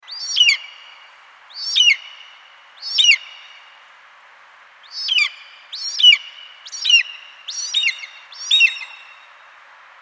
دانلود صدای عقاب سلطان آسمان از ساعد نیوز با لینک مستقیم و کیفیت بالا
جلوه های صوتی
برچسب: دانلود آهنگ های افکت صوتی انسان و موجودات زنده